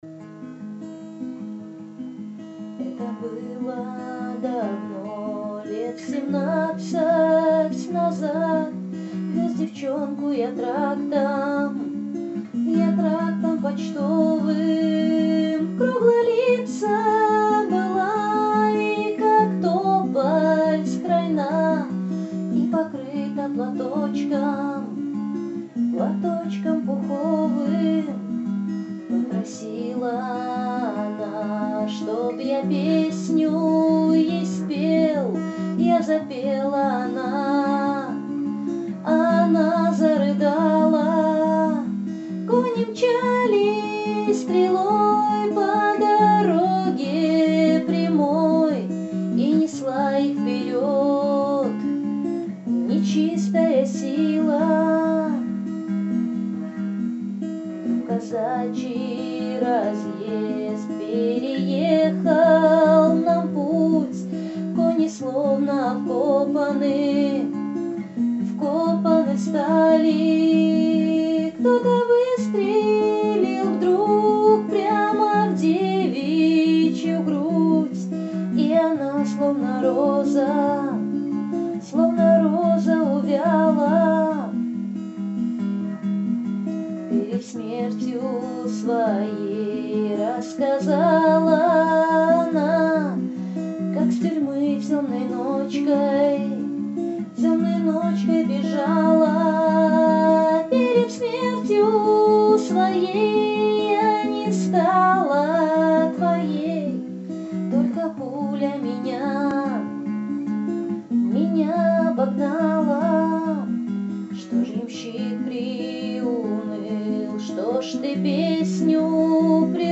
Гитара
Слушать на гитаре